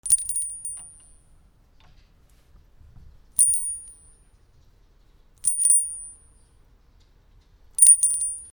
/ G｜音を出すもの / G-01 機器_ベル・非常ベル
小さな鈴
チリン